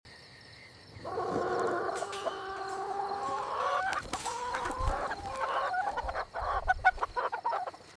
Fox sneaks into chicken coop sound effects free download
Fox sneaks into chicken coop at night (caught on night vision)